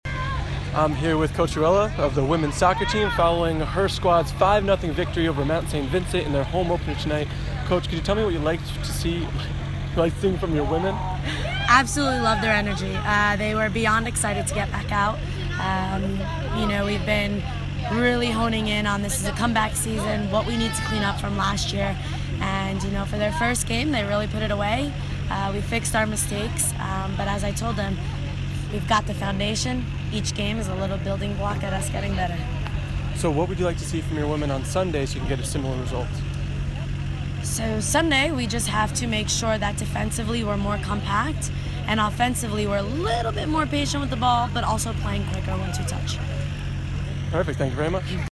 Post Game Audio